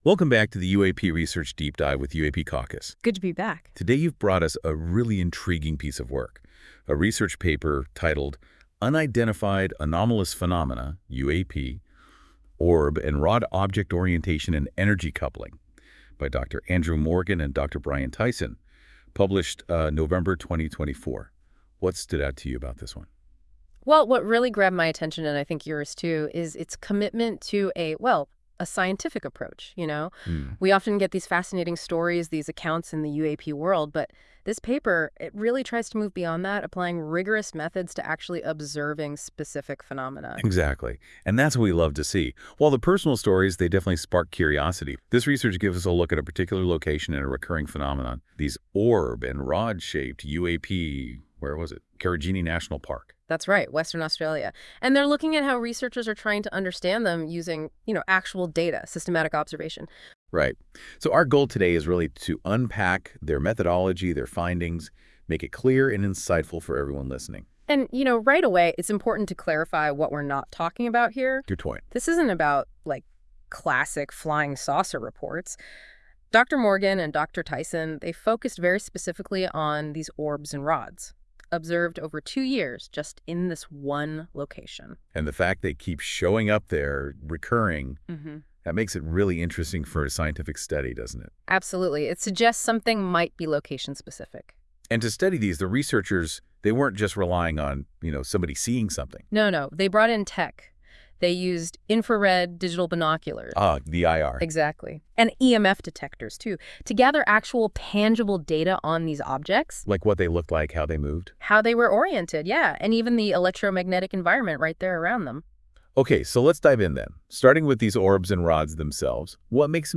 Powered by NotebookLM. This AI-generated audio may not fully capture the research's complexity.